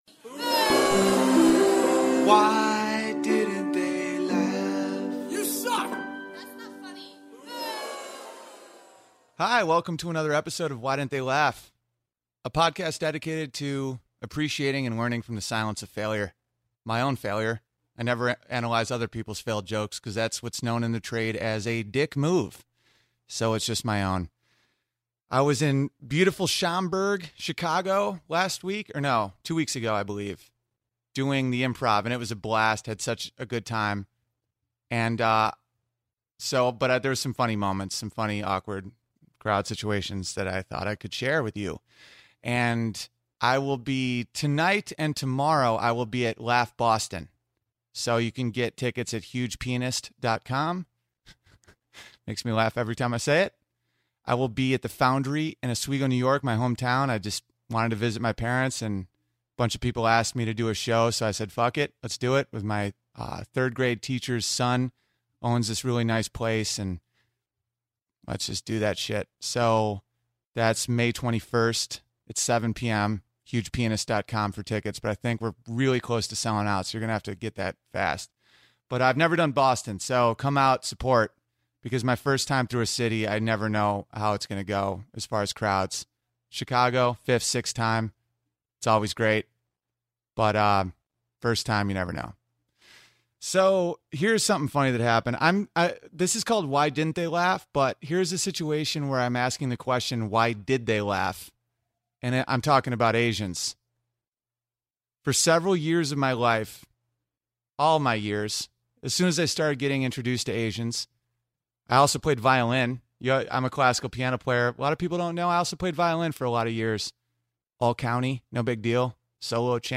A solo ep w stand up clips.